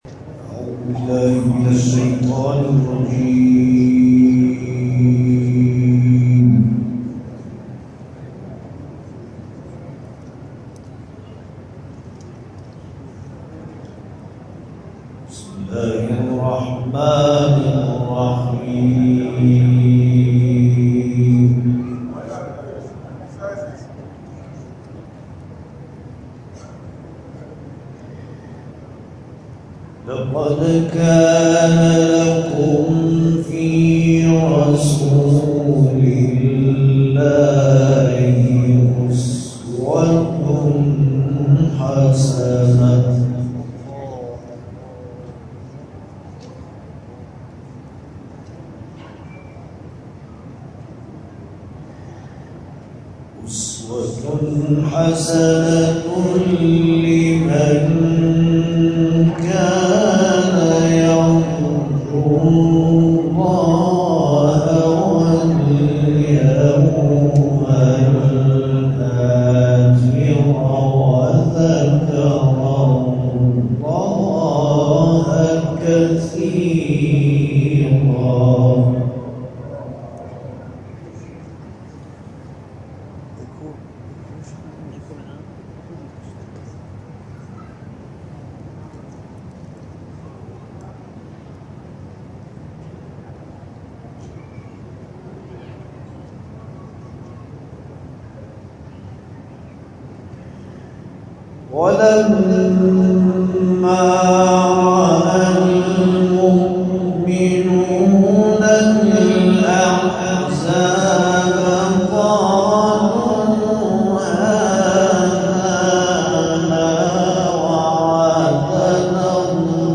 در مسجد شهر غرق آباد شهرستان ساوه برگزار گردید.
به تلاوت آیاتی از کلام الله مجید پرداخت.
دقایقی به روضه خوانی و مدیحه سرایی پرداخت.